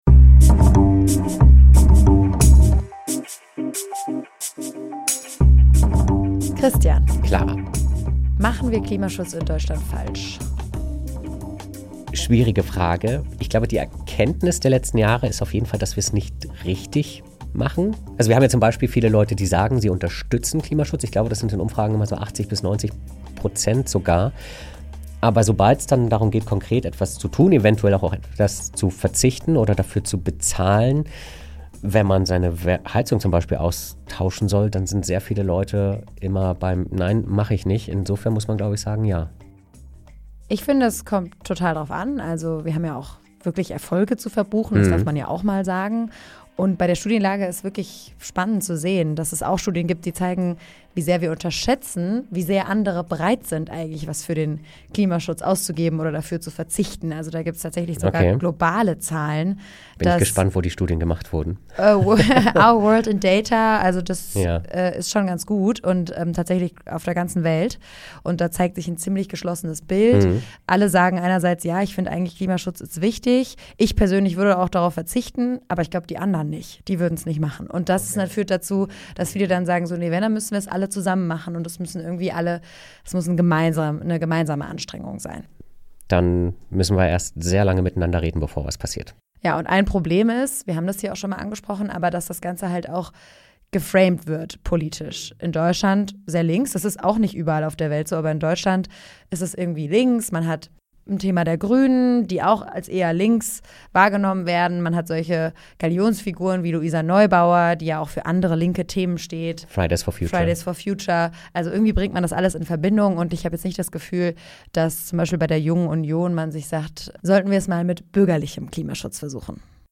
Dann bewertet das "Klima-Labor" bei Apple Podcasts oder Spotify Das Interview als Text?